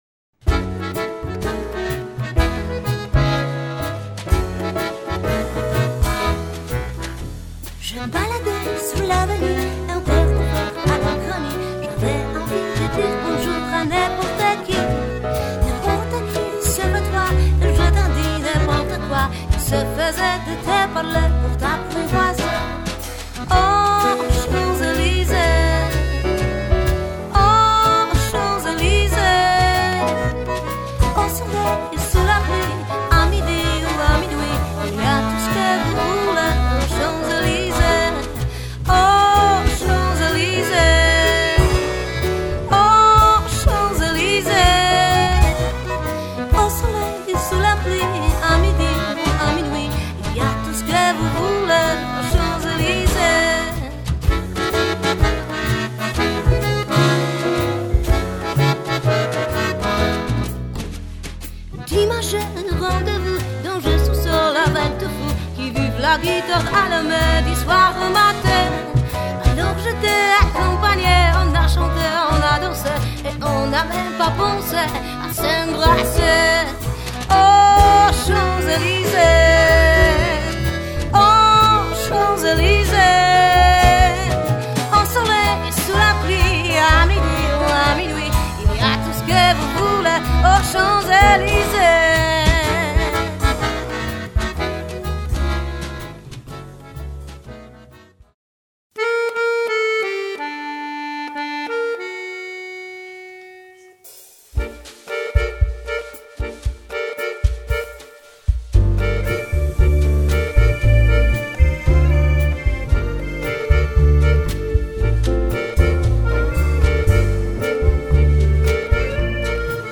Duet akordeon & kontrabas (muzyka francuska)
- standardy jazzowe